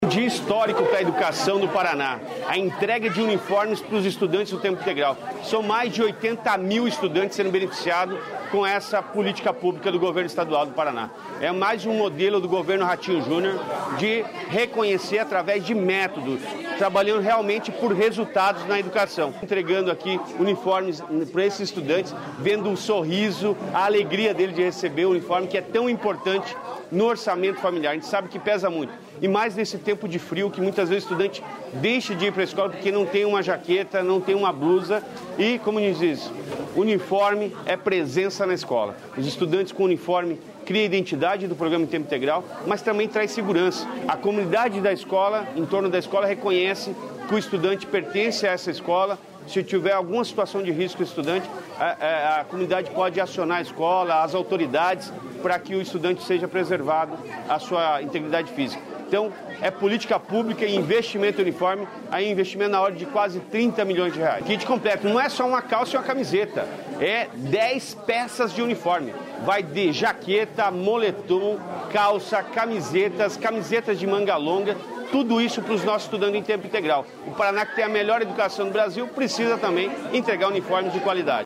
Sonora do secretário da Educação, Roni Miranda, sobre a entrega dos uniformes escolares